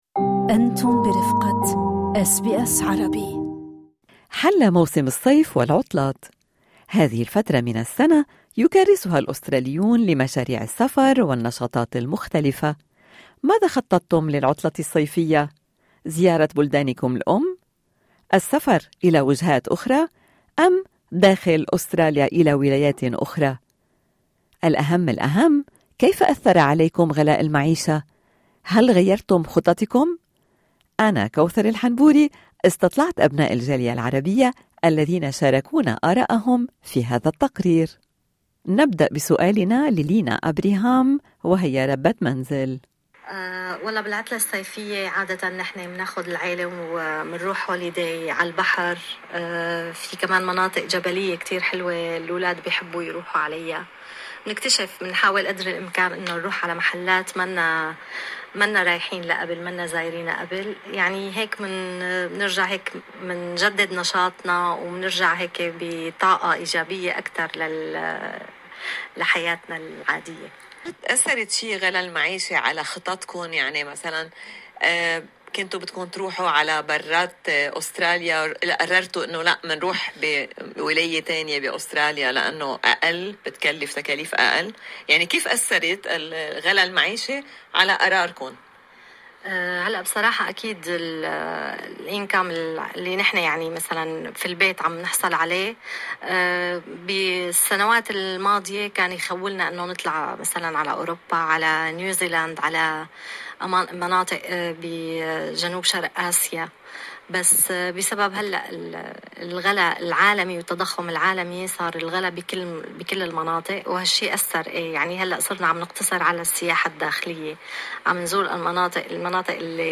والأهم كيف أثر غلاء المعيشة على على خططكم؟ استطلعنا أبناء الجالية العربية الذين شاركونا أراءهم...